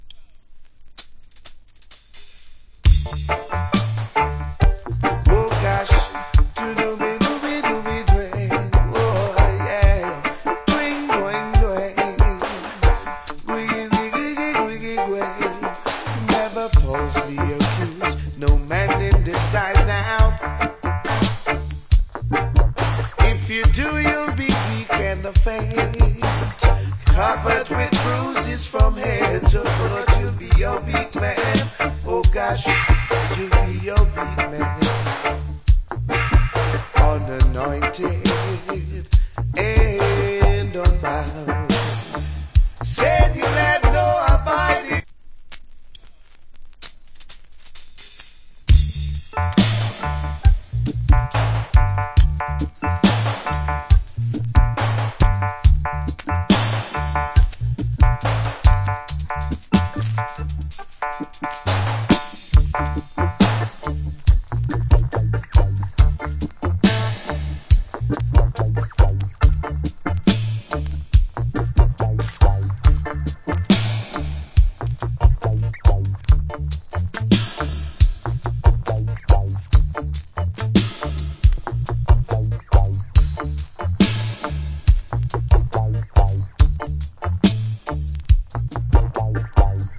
Early 80's. Wicked Reggae Vocal.